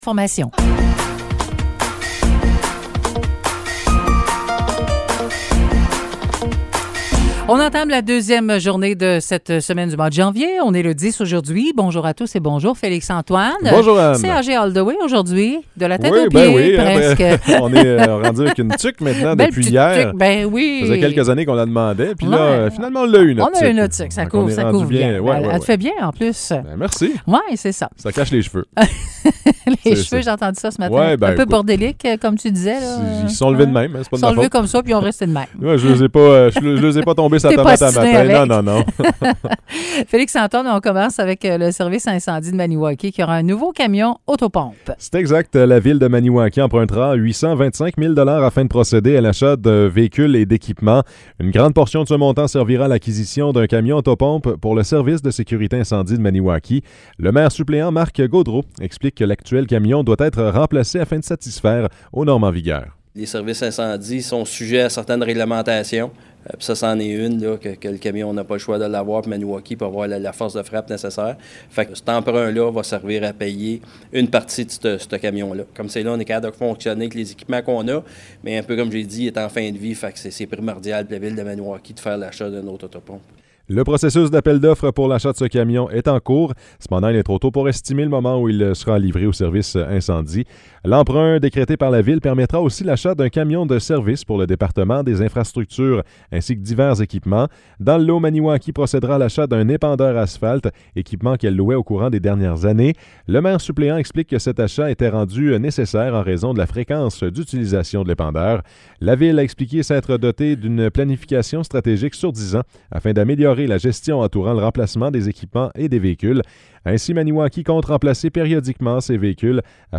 Nouvelles locales - 10 janvier 2023 - 9 h